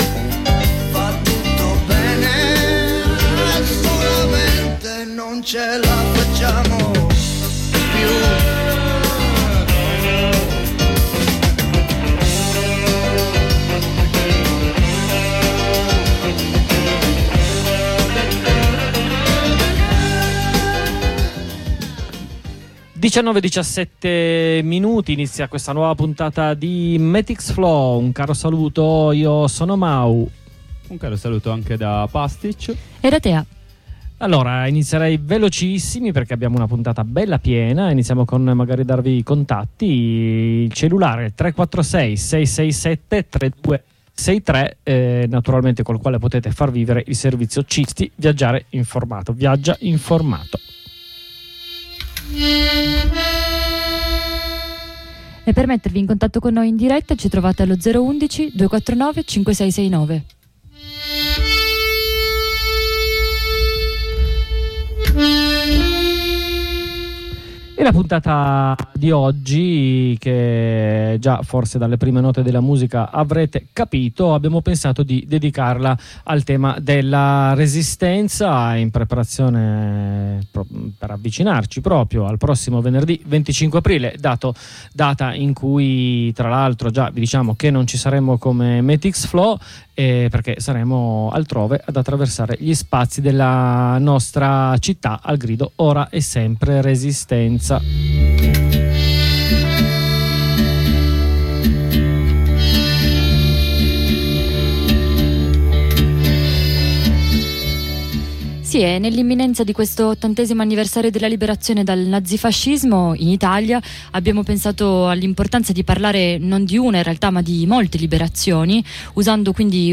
Dal Vietnam a Gaza, passando per la Resistenza dei nostri partigiani e partigiane. Abbiamo preparato la puntata con delle letture tratte da: - Un fiore che non muore - La voce delle donne nella resistenza a cura Ilenia...